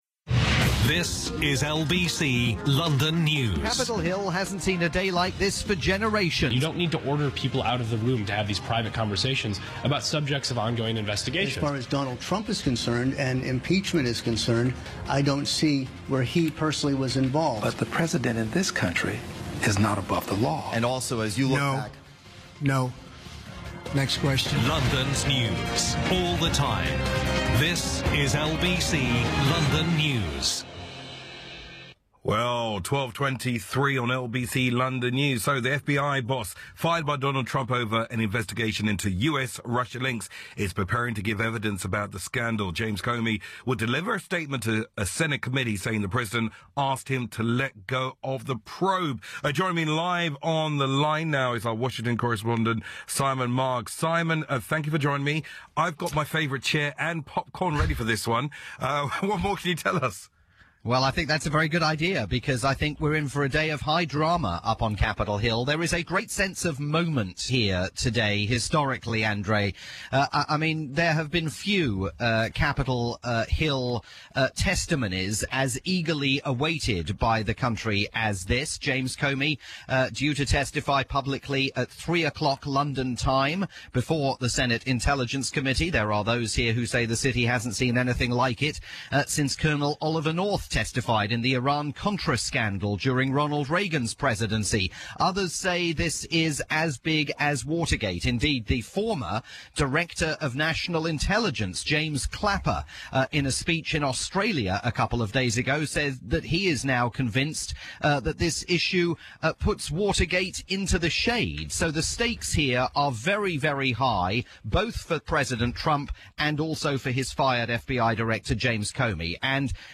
via LBC London News, the UK's rolling news station.